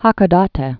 (häkô-dätĕ)